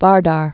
(värdär)